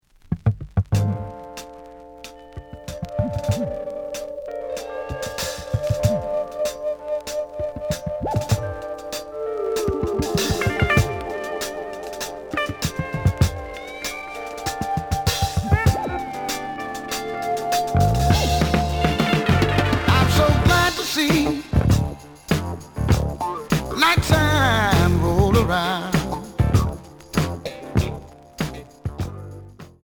(Mono)
試聴は実際のレコードから録音しています。
●Genre: Funk, 70's Funk